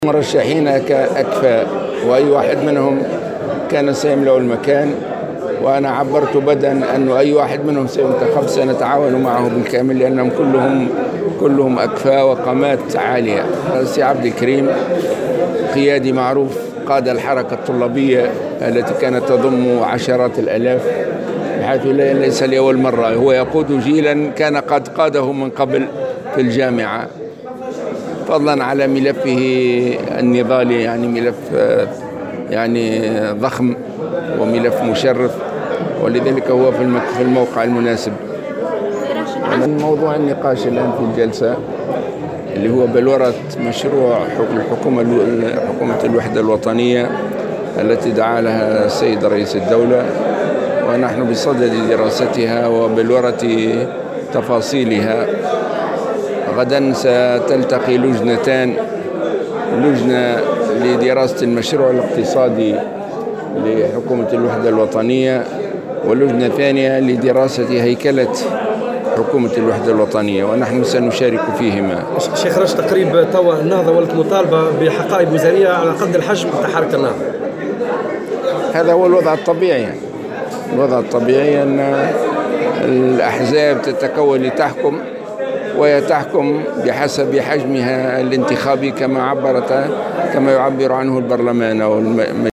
وأضاف في تصريحات صحفية على اثر اجتماع لمجلس شورى النهضة بالحمامات مساء اليوم أن لجنتين ستجتمعان يوم غد لدراسة كل من هيكلة حكومة الوحدة الوطنية ومشروعها الاقتصادي وستشارك فيهما حركة النهضة.